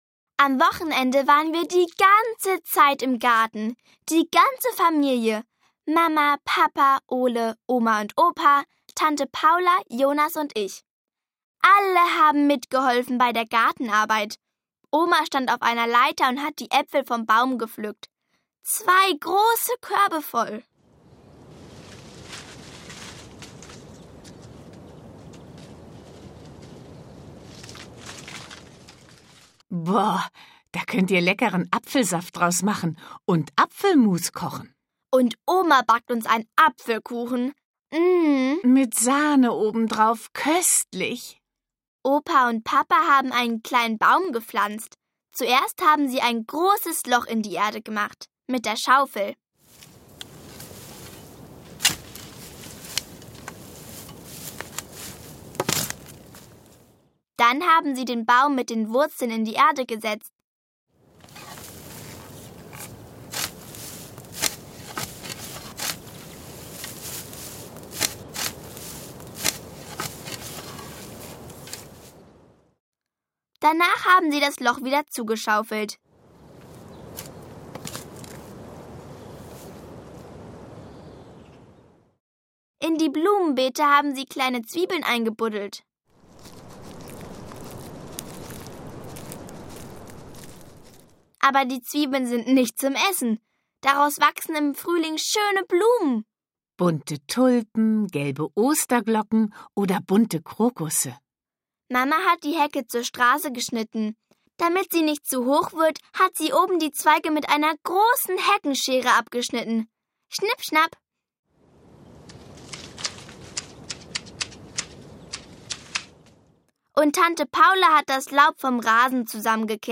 Altersgerechte Fragen und Antworten, authentische Geräusche, viele Mitmach-Aktionen und Musik machen die Reihe "Wieso? Weshalb? Warum? junior" zu einem Hörerlebnis für die Kleinsten.
Schlagworte Beschäftigung • Beschäftigung im Herbst • Drachen fliegen • Gummistiefel • Halloween • Herbst; Kindersachbuch/Jugendsachbuch • Hörbuch; Hörspiel für Kinder/Jugendliche • Jahreszeiten • Kastanien • Kinderalltag • Kleidung • Laternenumzug • Laub • Pfützen